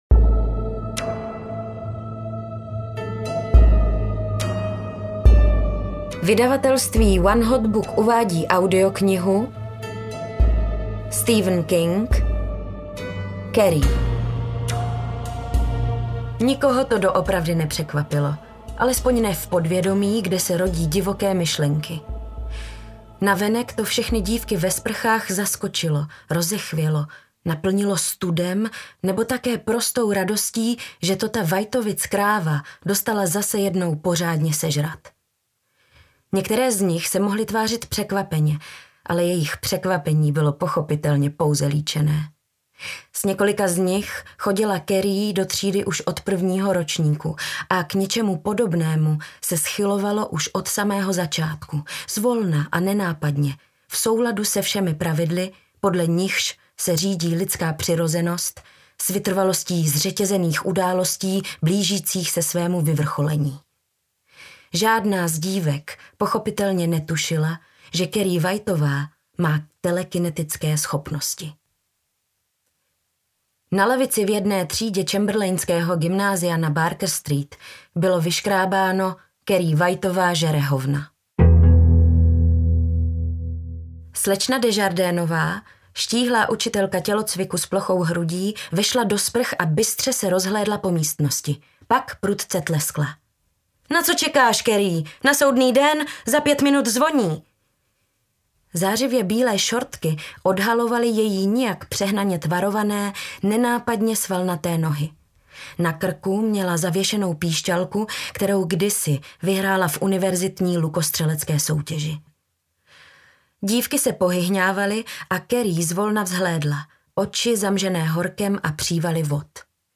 Carrie audiokniha
Ukázka z knihy